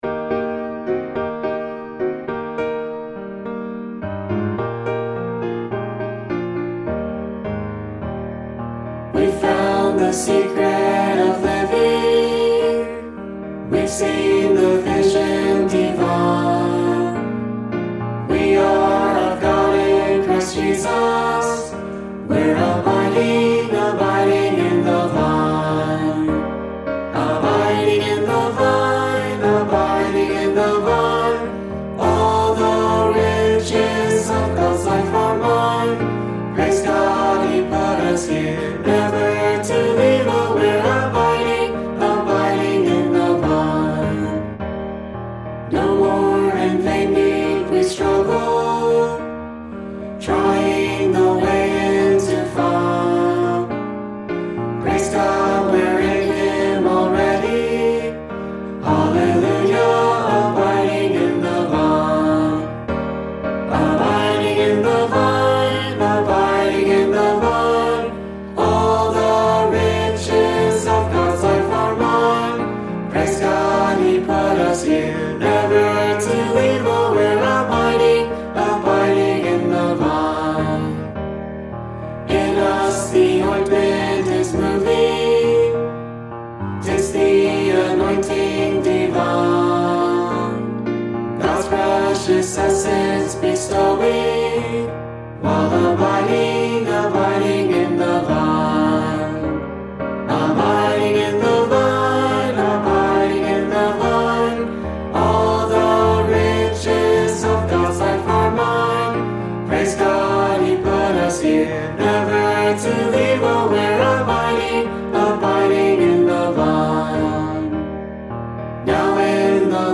Hymn: We’ve found the secret of living
e1162_harmony.mp3